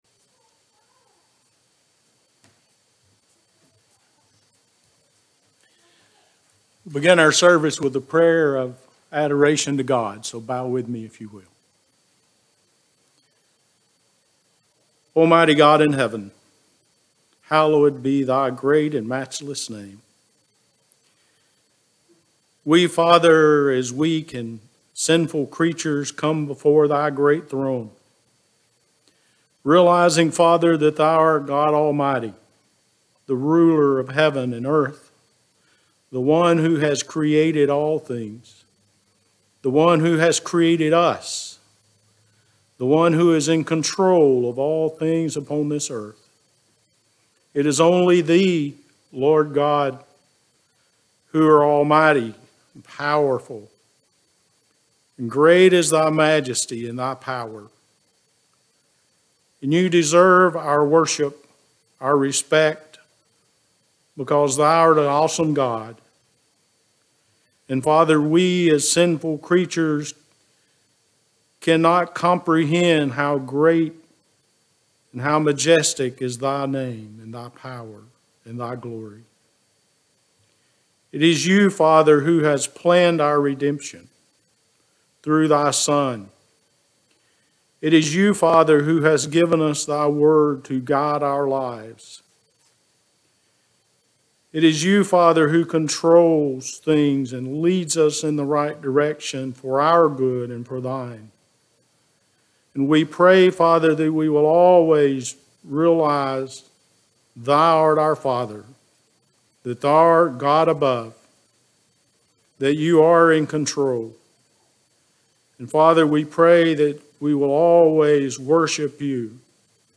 Sunday PM 12.26.21 – Prayer Service